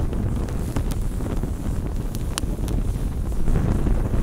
Flame1.wav